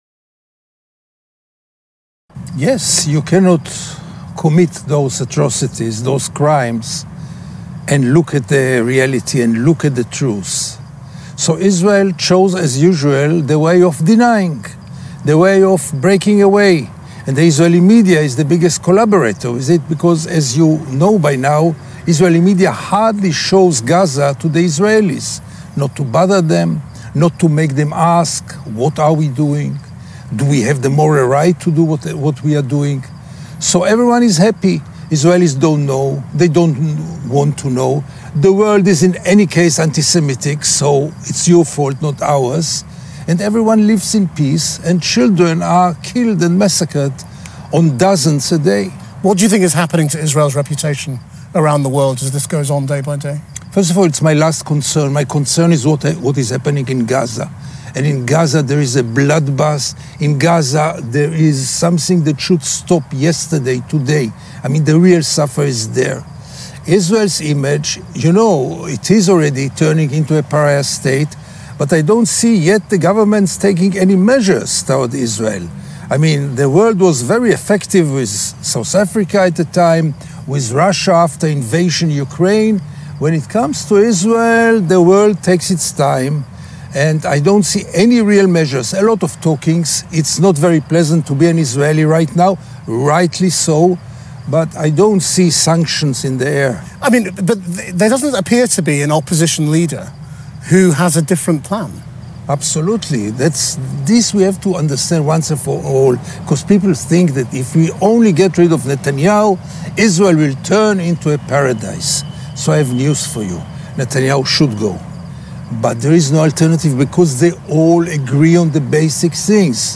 مصاحبه تلویزیون کانال4 با گیدن لوی – ژورنالیست اسرائیلی – اخبار
We were joined in Tel-Aviv by Gideon Levy, a journalist for the Israeli newspaper Haaretz.